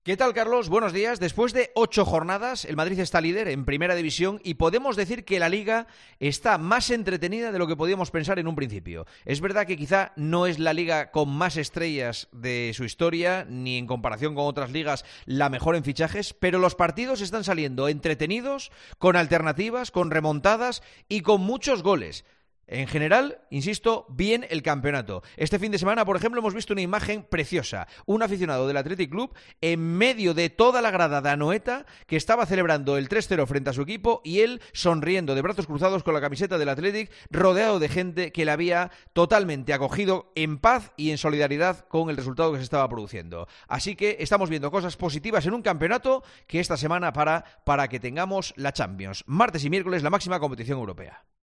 El comentario de Juanma Castaño
El presentador de 'El Partidazo de COPE' analiza la actualidad deportiva en 'Herrera en COPE'